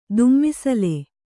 ♪ dummisale